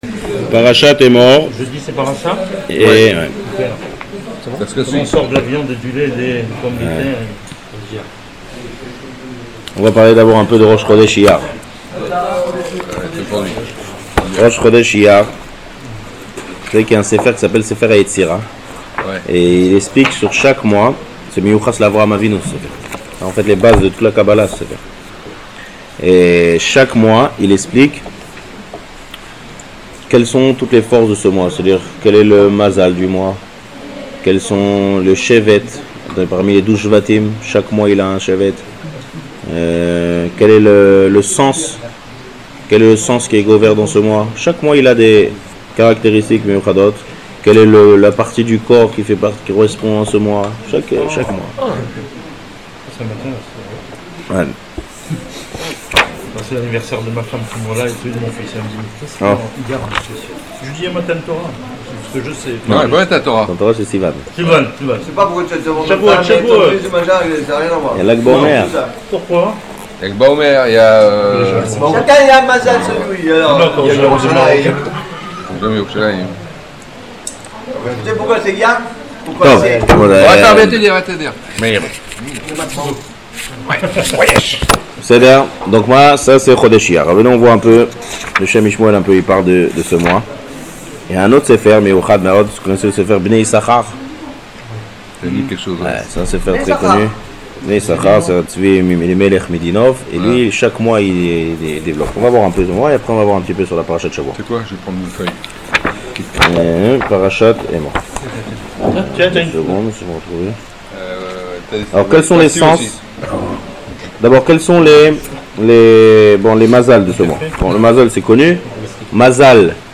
Cours sur la Parashath EMOR basé sur le commentaire du Kéli Yaqar (donné le jeudi à Raanana)